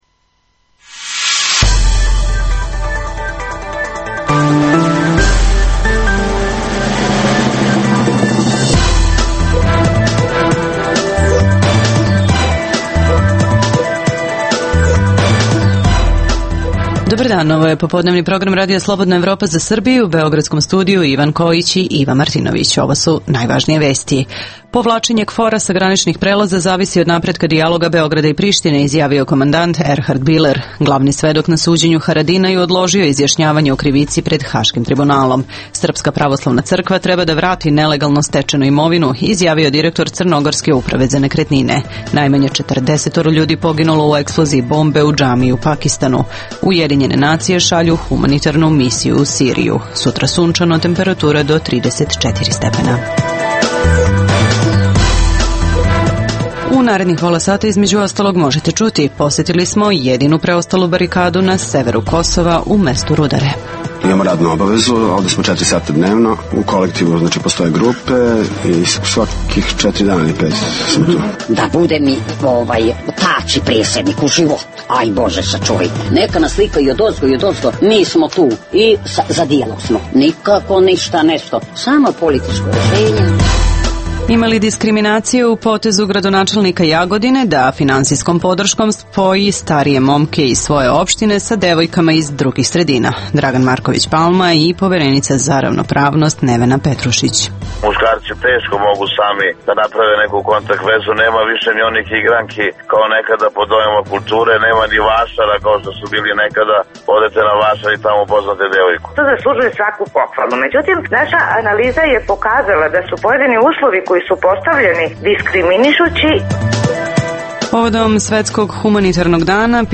U ovoj emisiji možete čuti: - Istražujemo da li i ko iz Srbije podržava kriminalne strukture na severu Kosova? - Čućete i reportažu sa graničnih prelaza.